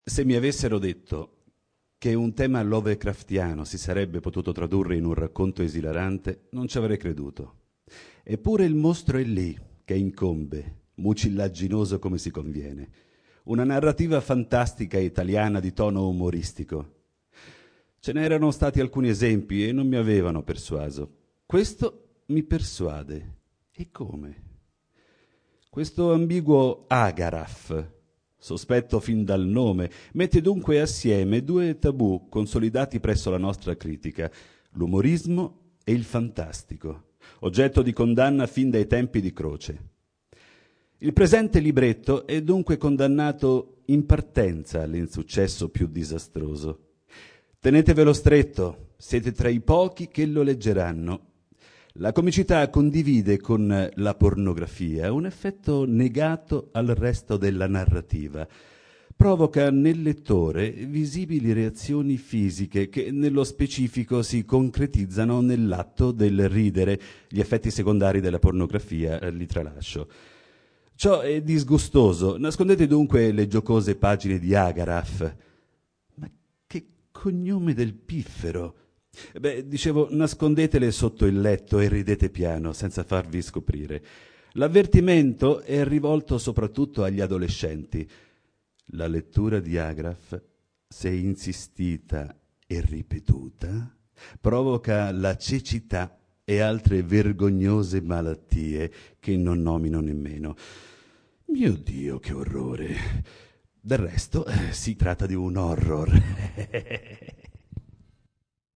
Letture di brani scelti